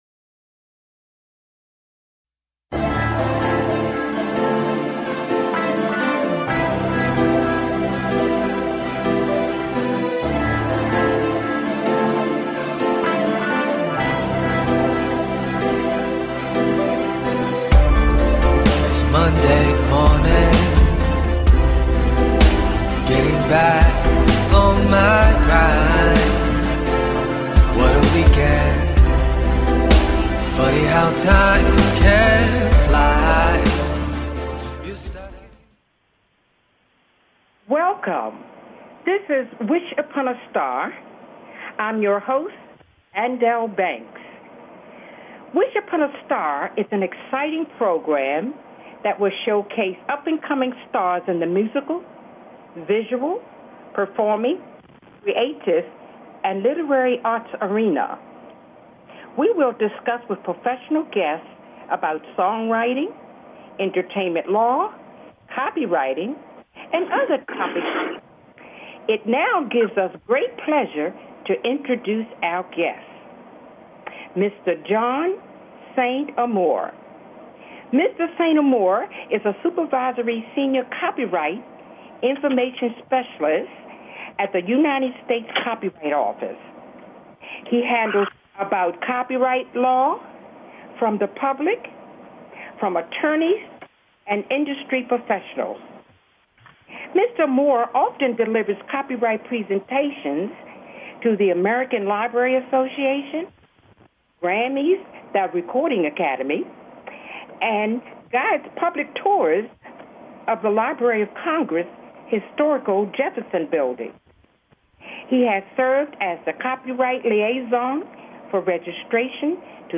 Talk Show Episode
We will interview professional guests in the fields of entertainment law, copyrighting, studio recording, songwriting, publishing, and other topics in these highly creative and challenging fields."